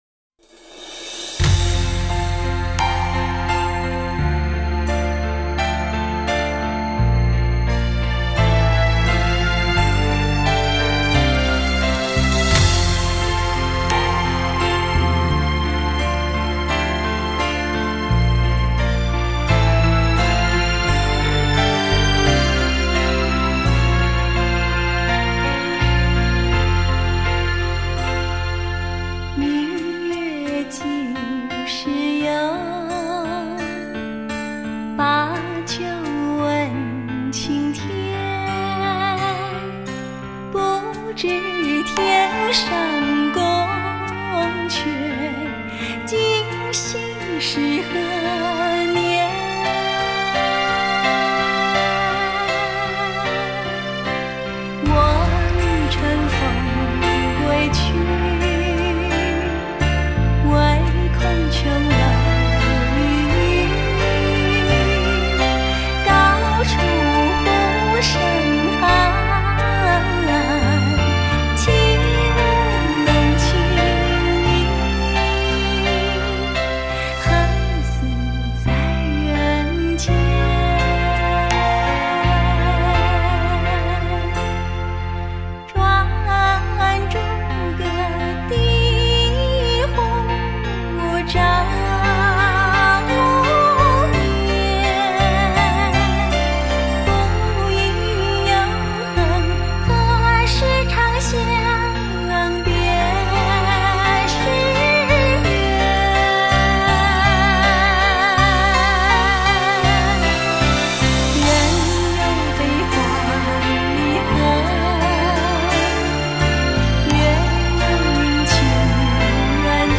70年代电影金曲 文艺电影 绕梁名曲
24BIT数码高频 原音毕露